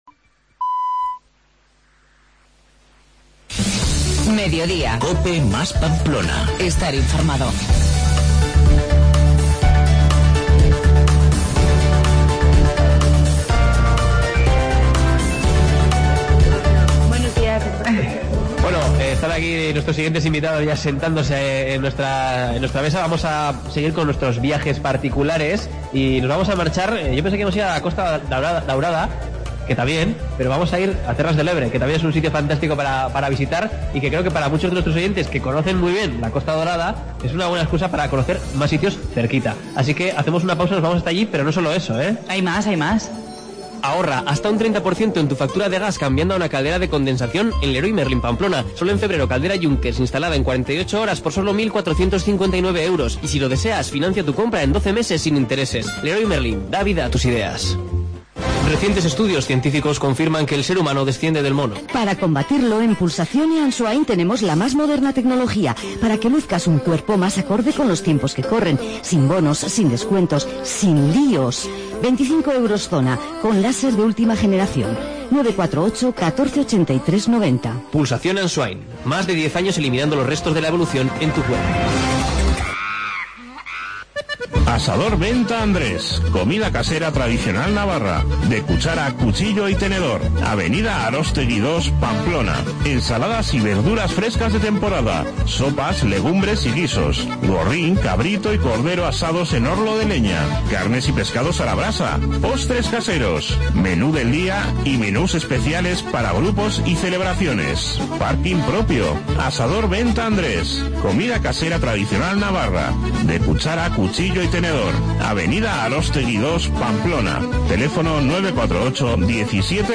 AUDIO: desde NAVARTUR